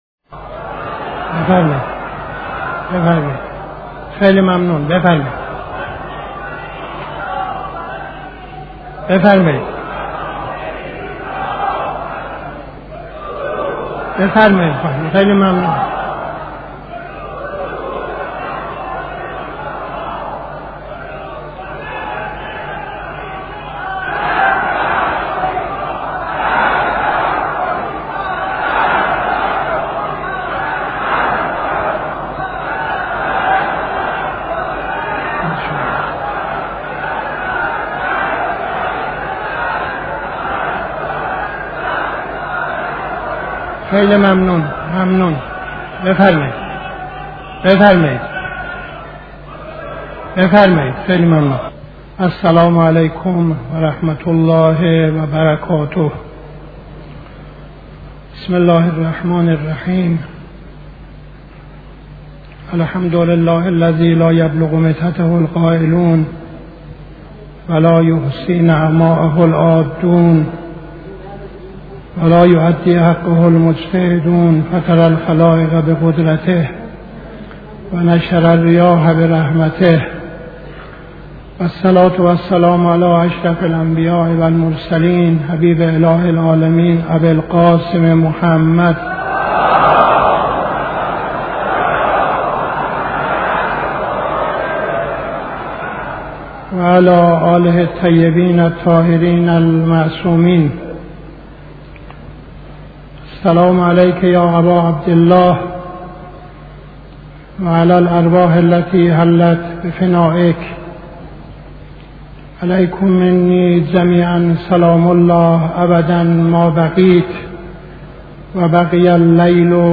خطبه اول نماز جمعه 12-04-71